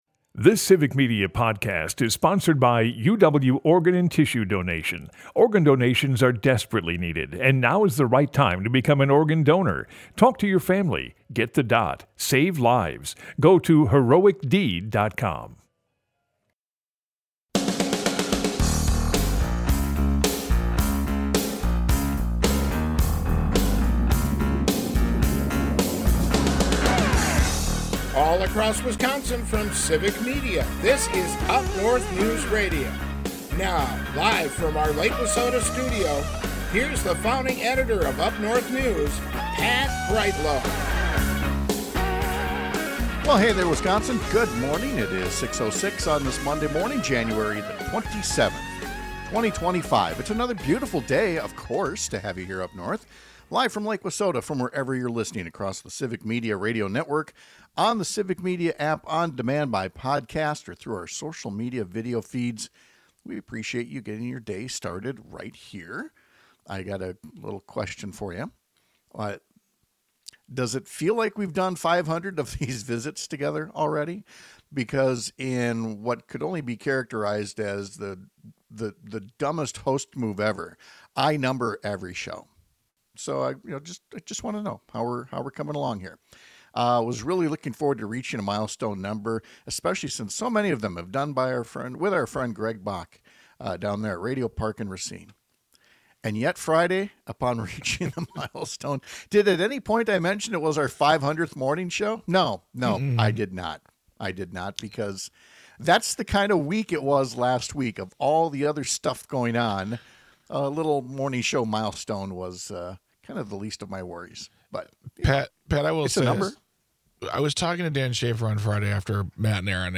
Broadcasts live 6 - 8 a.m. across the state!
UpNorthNews with Pat Kreitlow airs on several stations across the Civic Media radio network, Monday through Friday from 6-8 am.